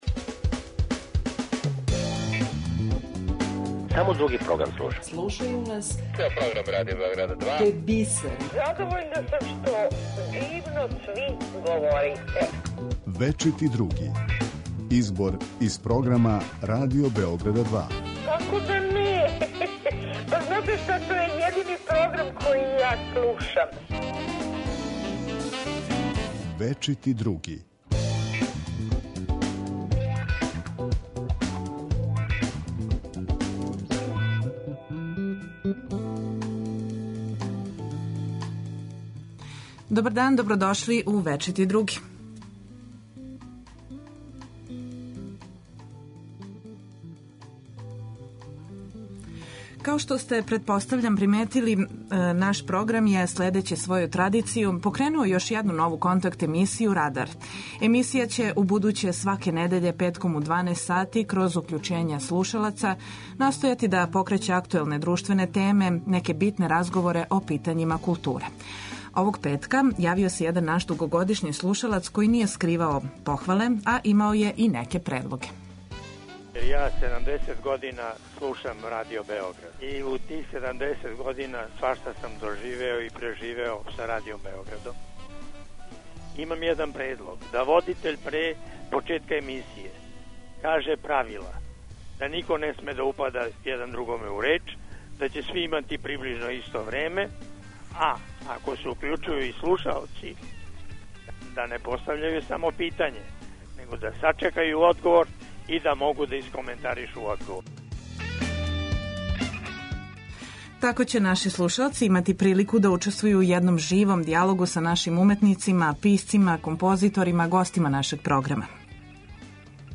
У емисији „Вечити други“ слушаоци ће моћи да чују избор најзанимљивијих садржаја емитованих на програму Радио Београда 2 током претходне и најаву онога што ће бити на програму идуће седмице.